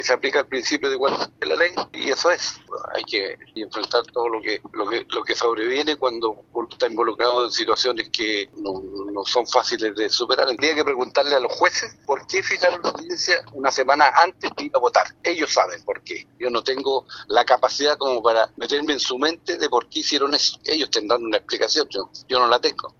Consultado por el tema, el senador Socialista, Gastón Saavedra, cuestionó la decisión del magistrado en reprogramar la audiencia, dada la cercanía con los comicios.